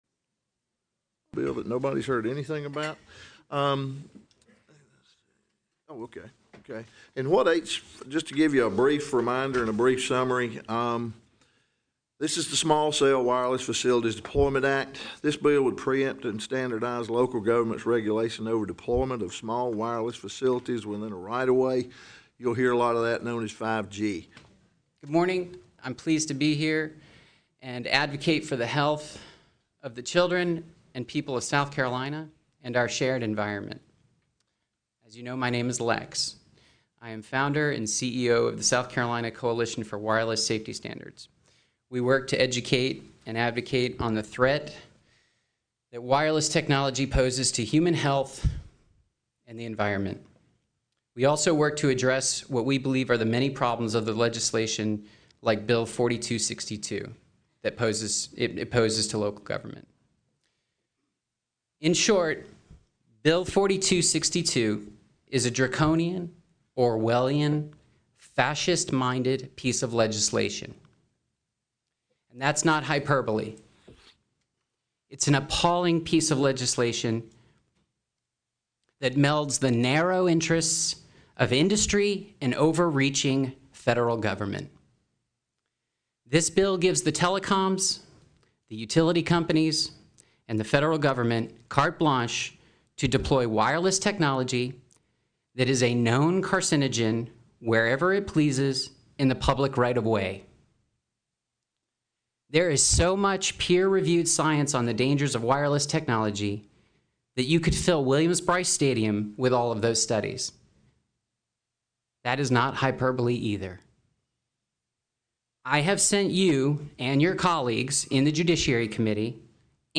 South Carolina Statehouse Testimony on 5G